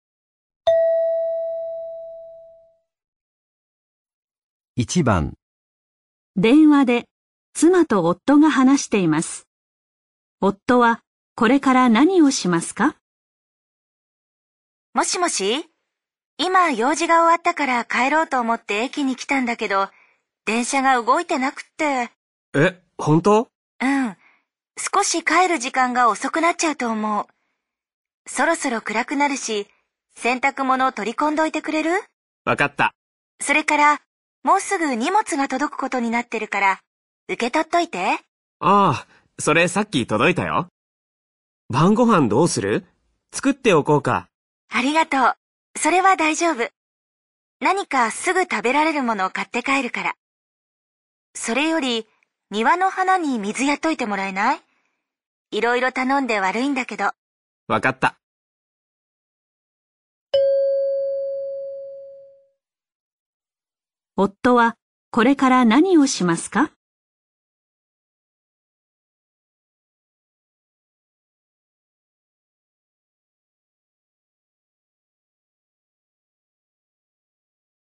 妻子和丈夫在电话里交谈。